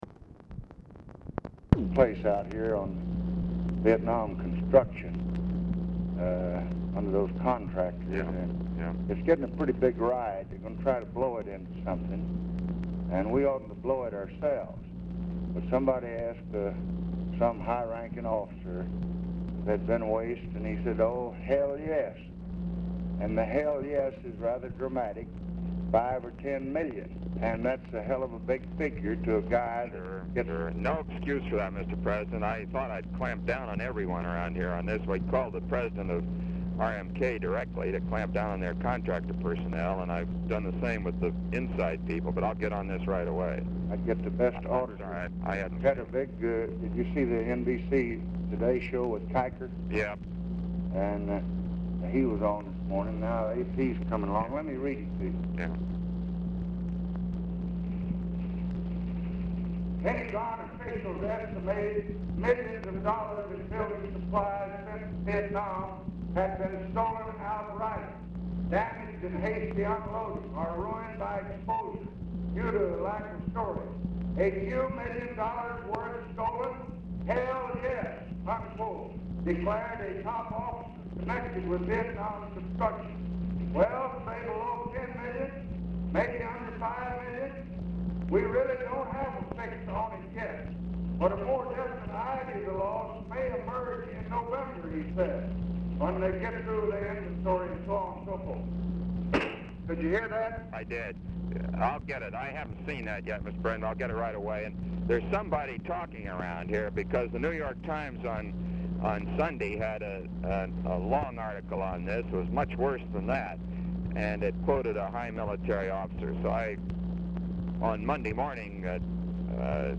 Telephone conversation
RECORDING STARTS AFTER CONVERSATION HAS BEGUN; LBJ IS APPARENTLY ON SPEAKERPHONE AND IS DIFFICULT TO HEAR
Format Dictation belt